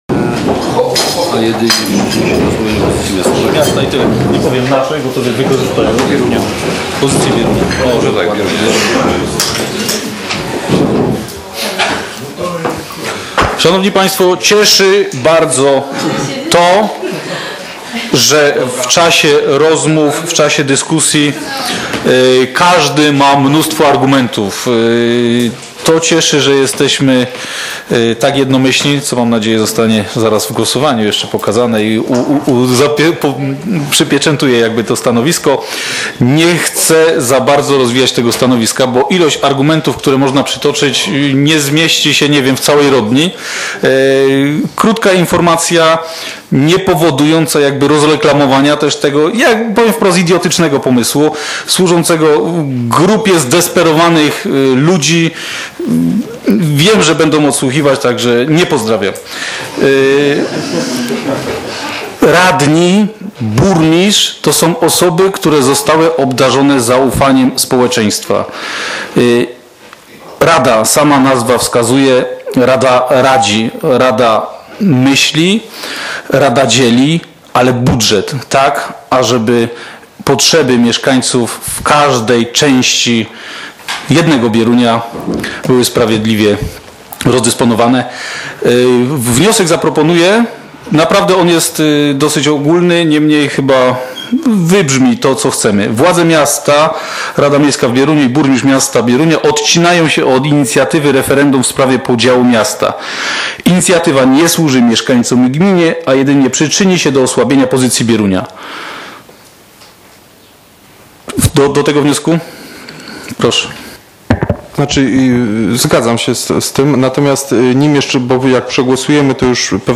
z obrad VI sesji Rady Miejskiej w Bieruniu, która odbyła się w dniu 25.06.2015 r. w dużej sali szkoleń Urzędu Miejskiego w Bieruniu